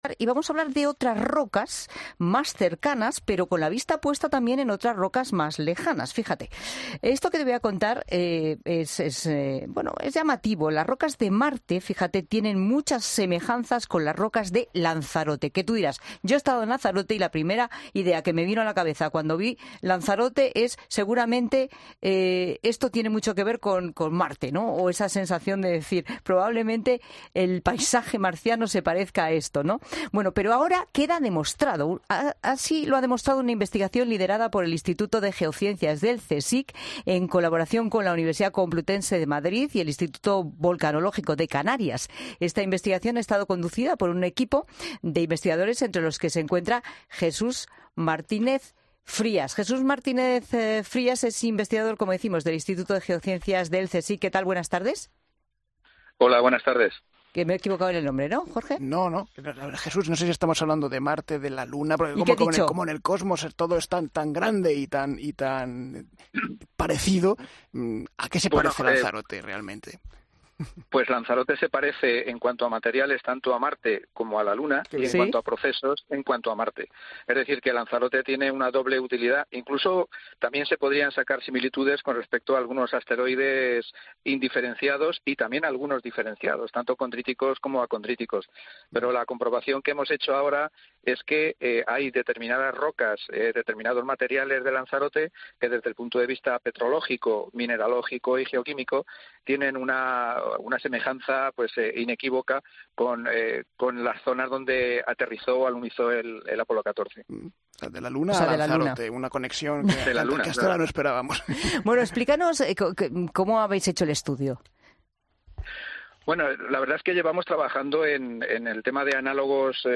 Un investigador del CSIC habla sobre la relación que hay entre Lanzarote y Marte: "Este es el resultado"